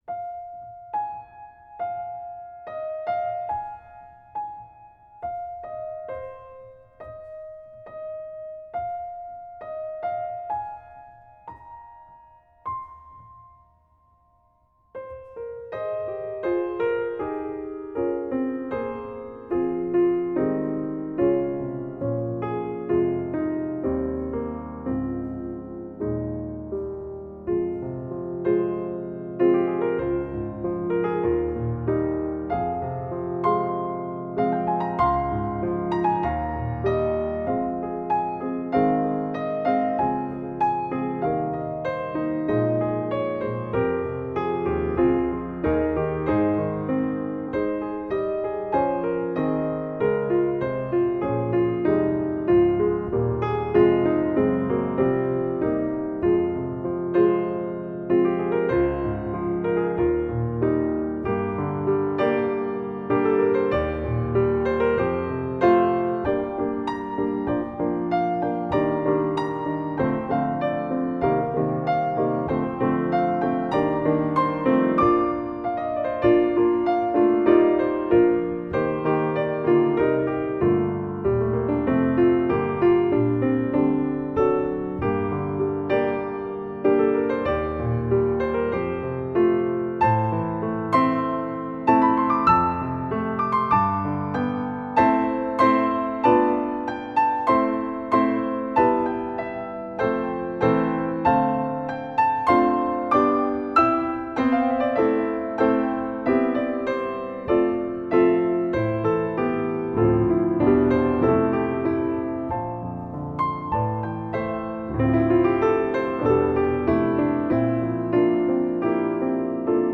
Voicing: Piano Solo Collection